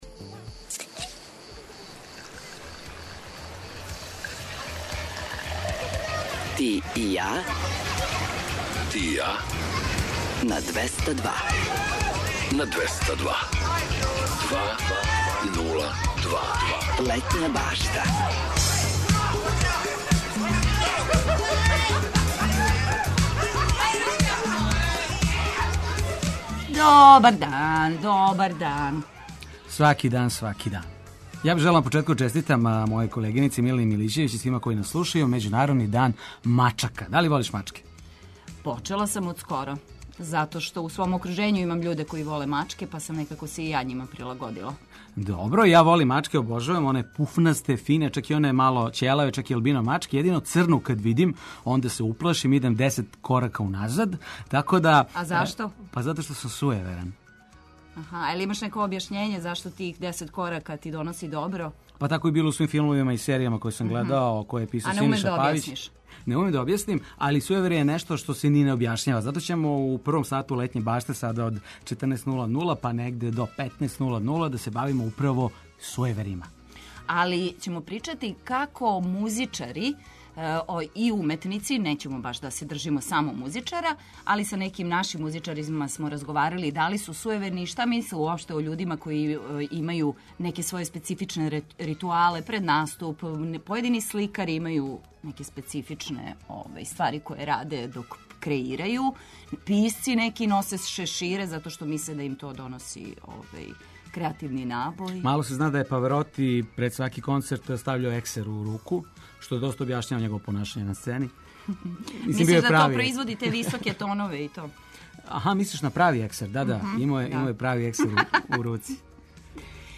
У Летњој башти чућете још занимљивих примера специфичних ритуала, чућете зашто то раде, а неки наши музичари причали су нам о сујеверју међу уметницима.